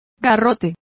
Also find out how garrote is pronounced correctly.